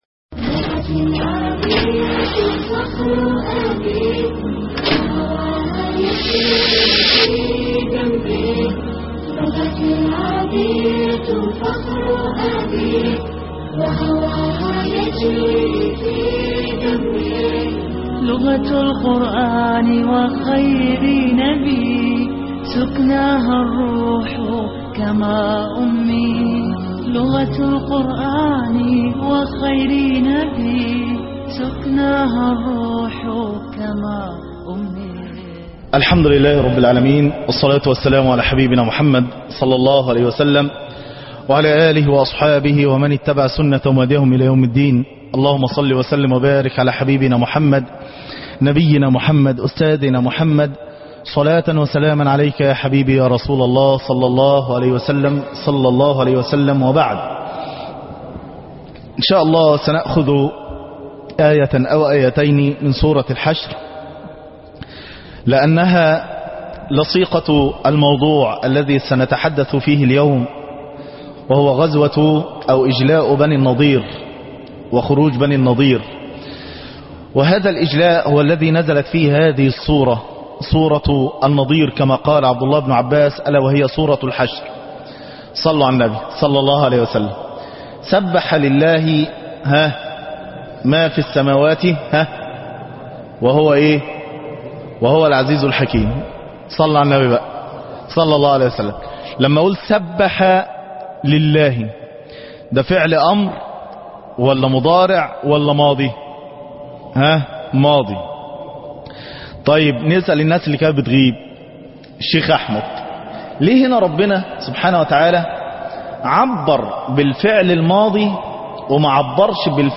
المحاضرة السابعة عشر- لغة القرءان